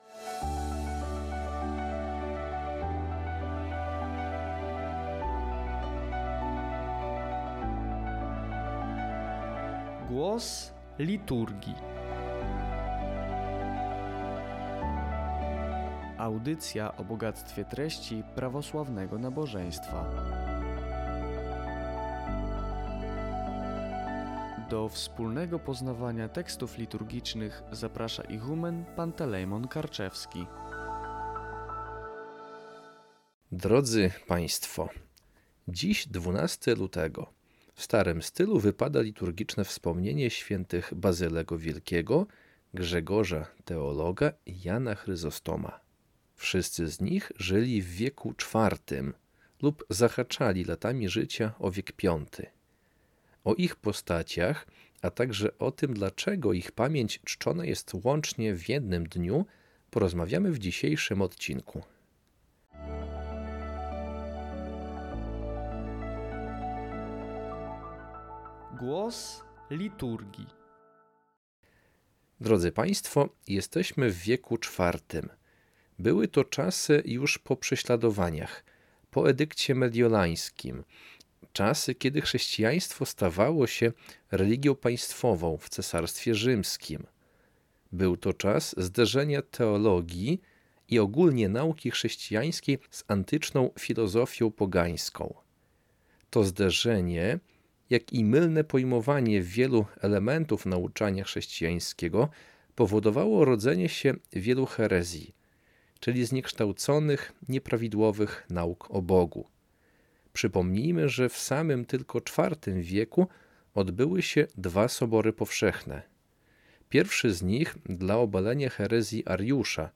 Audycja „Głos Liturgii” co dwa tygodnie.